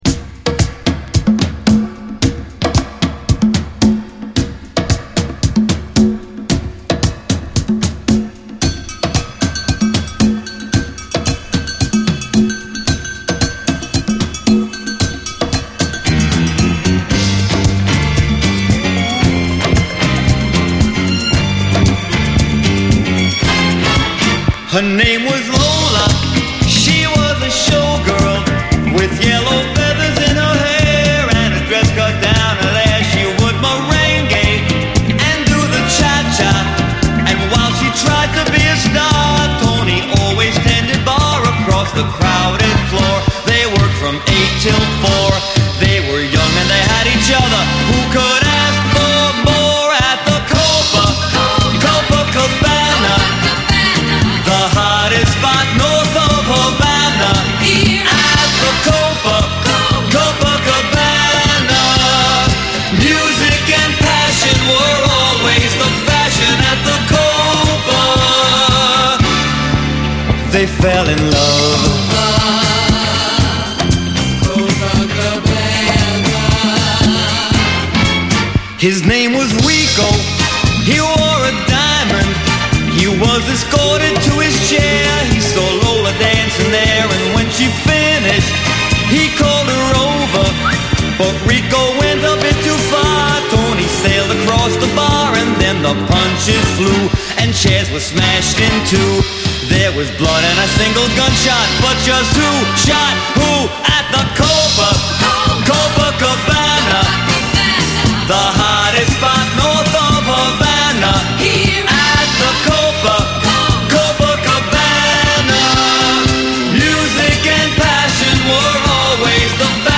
SOUL, 70's～ SOUL, DISCO, 7INCH